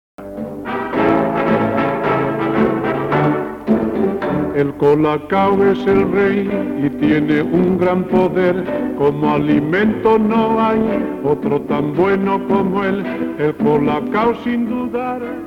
a ritme de txa-txa-txa